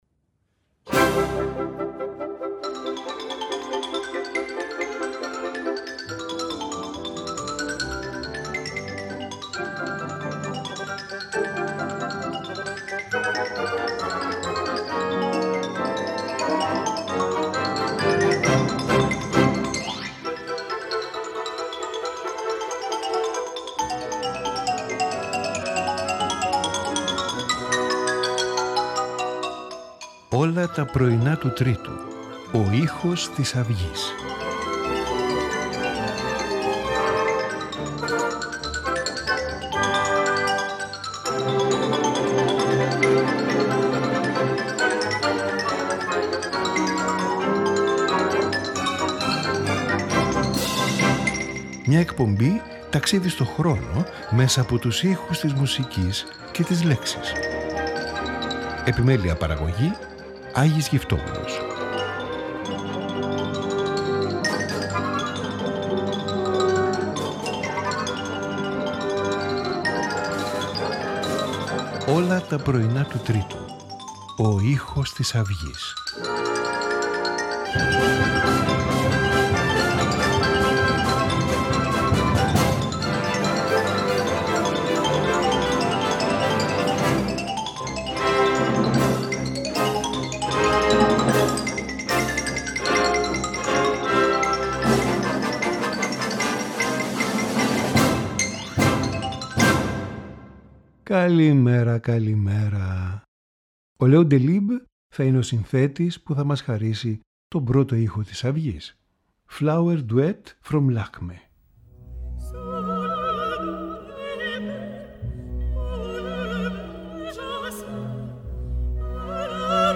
Piano Sonata No.21 in B-flat major
Cello Concerto No.1 in E-flat major
Concerto for Violin and Orchestra
Oboe Quartet
Flute Concerto in C major